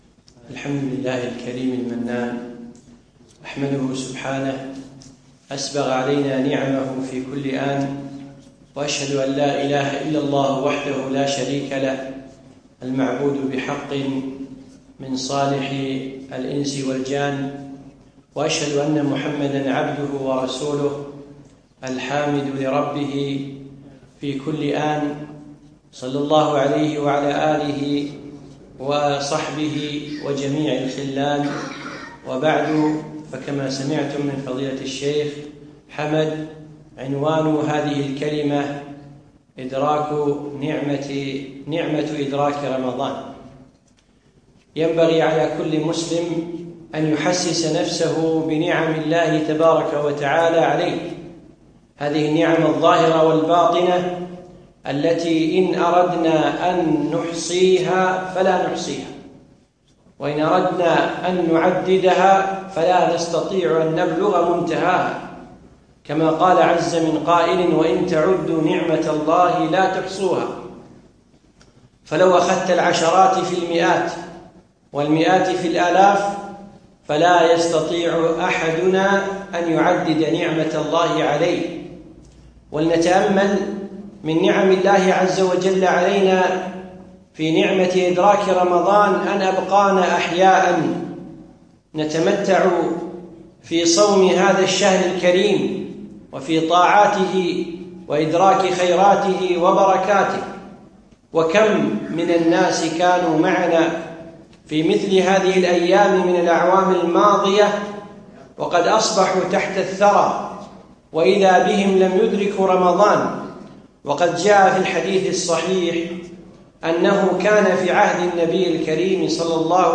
يوم الخميس 26 شعبان 1437هـ الموافق 2 6 2016م في مسجد طيبة النصرالله صباح السالم